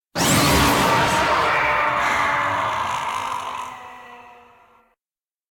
Kutuura_Roar.ogg